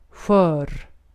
Recordings and example transcriptions in this help are in Sweden Swedish, unless otherwise noted.
skör, station, pension, geni, choklad[2] somewhat like Scottish loch or sheep (varies regionally)